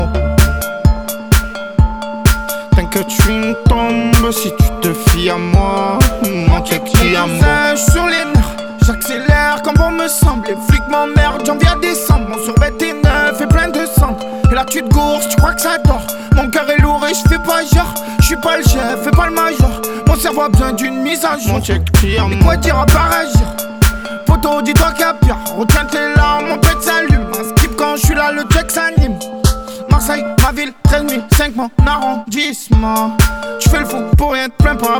# Хип-хоп